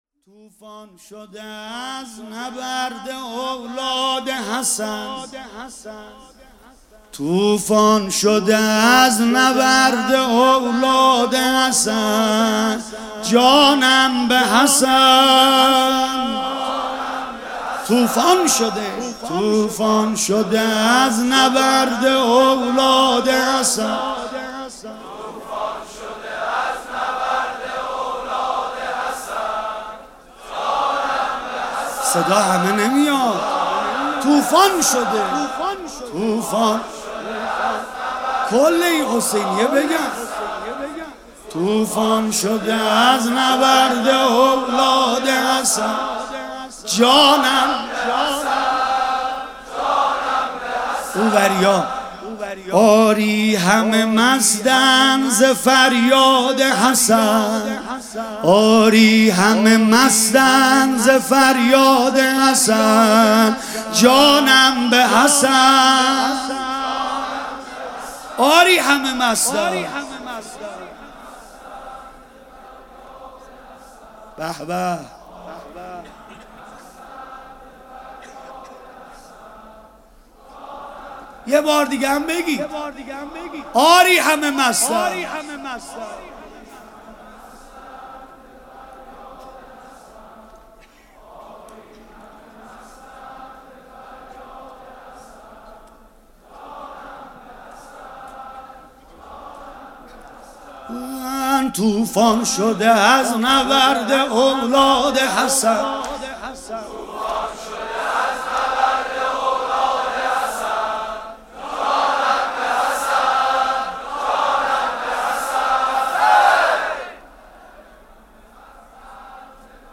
سیدرضانریمانی